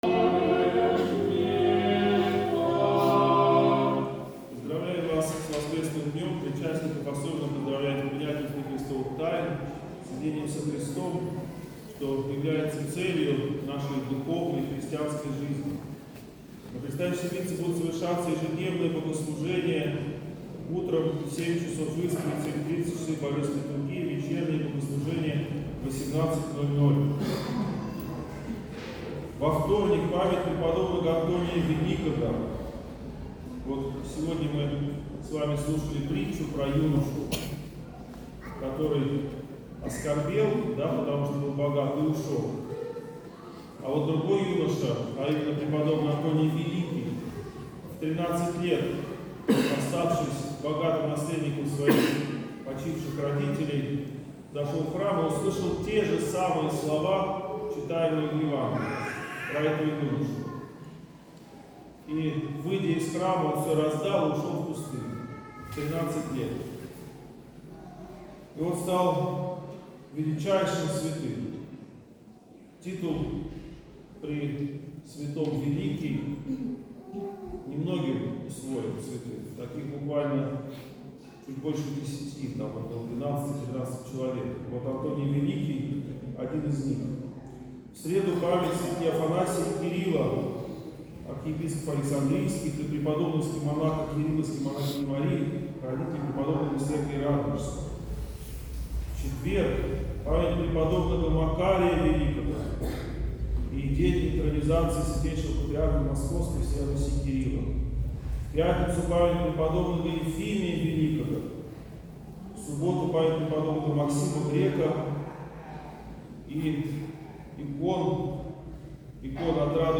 Проповедь в Неделю 31-ю по Пятидесятнице.